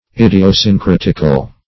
Idiosyncratical \Id`i*o*syn*crat"ic*al\
idiosyncratical.mp3